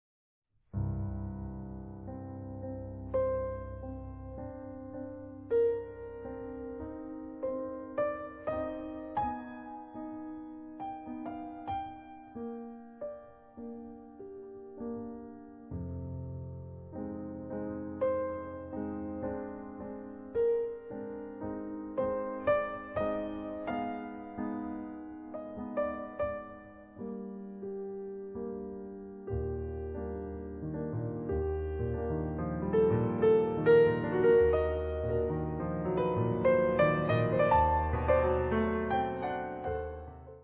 A short piece for solo piano.